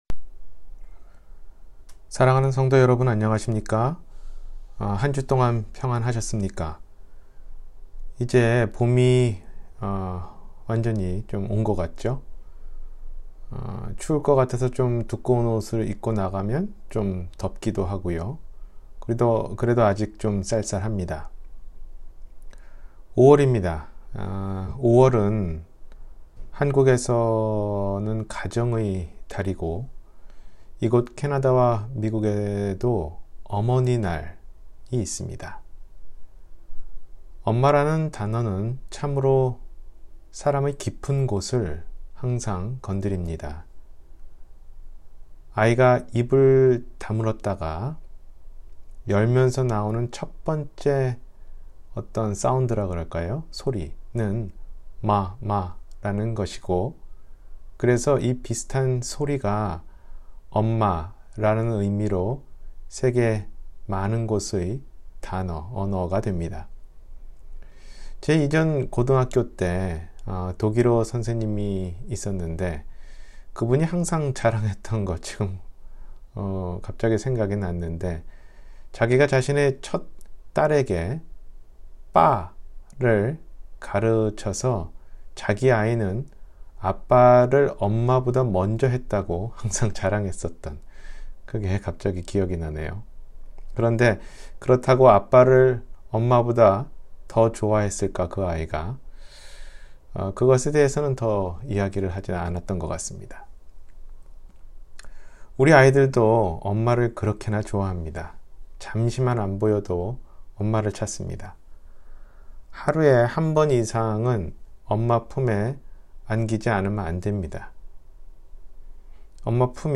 예레미야와 어머니 – 주일설교